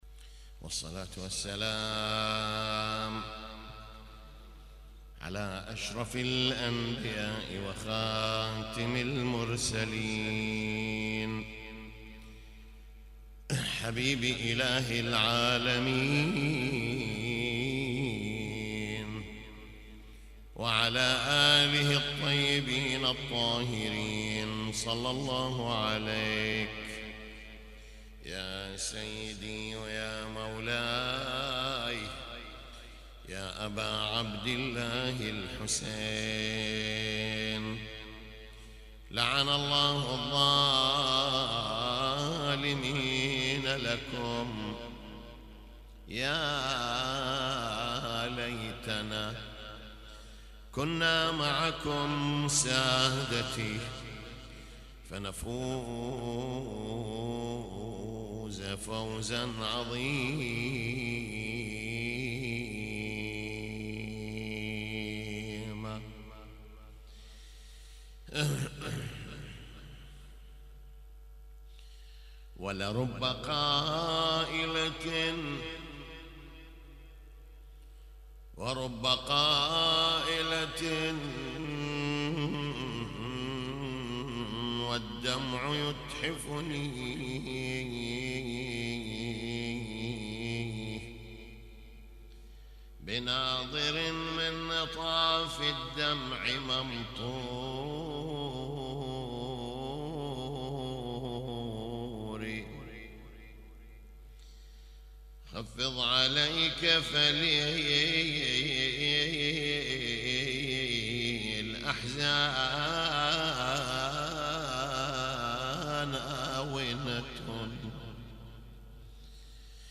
تغطية صوتية: يوم ثاني محرم 1438هـ في المأتم
يوم 2 محرم 1438هـ - مجلس العزاء